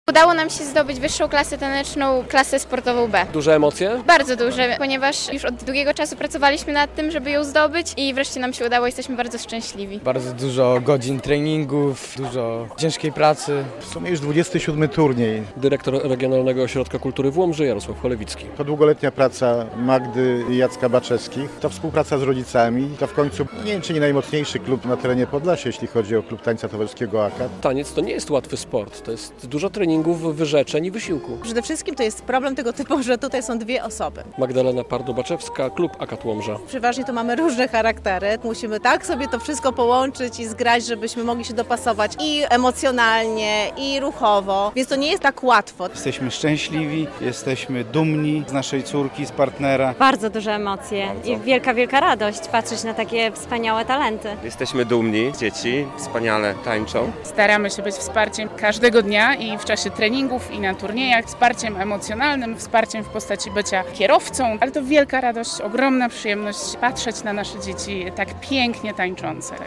Turniej tańca w Piątnicy - relacja
Turniej w hali sportowej w Piątnicy organizuje klub Akat, a rywalizacja podzielona jest ze względu na klasę tancerzy i ich wiek.